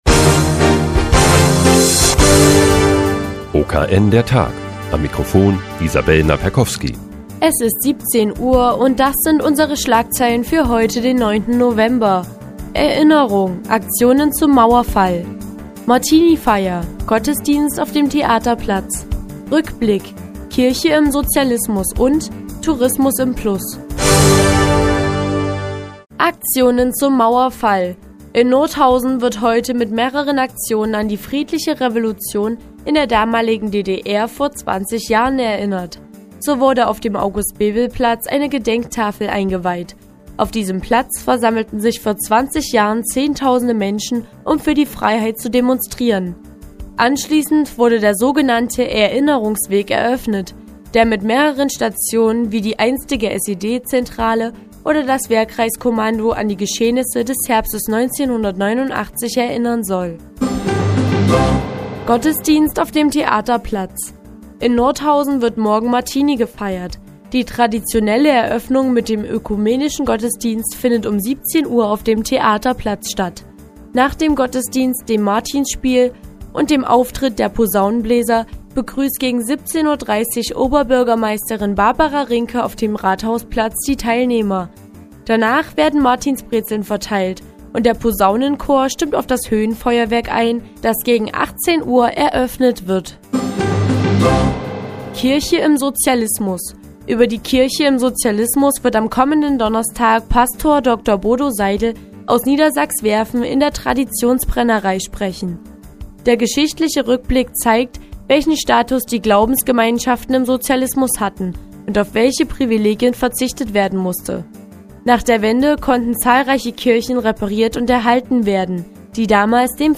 Die tägliche Nachrichtensendung des OKN ist nun auch in der nnz zu hören. Heute geht es um das Gedenken zum Mauerfall vor 20 Jahren und die Martinifeier in Nordhausen.